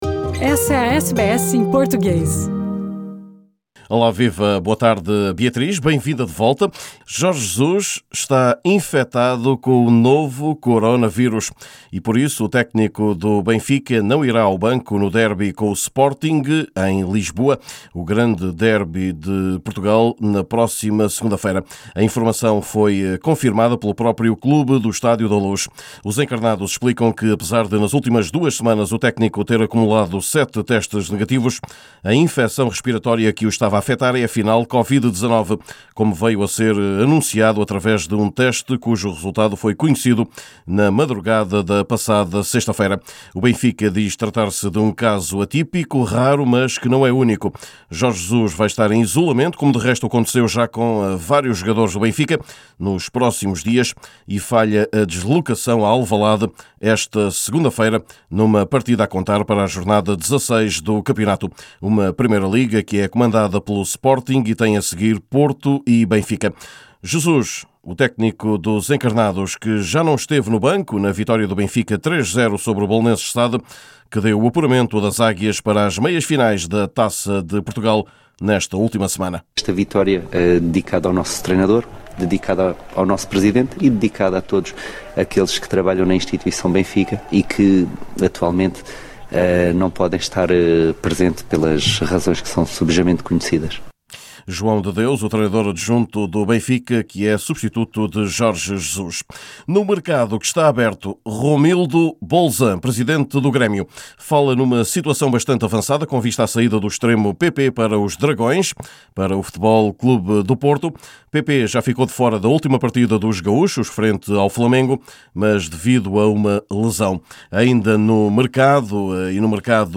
Saiba mais detalhes no boletim do nosso correspondente em Portugal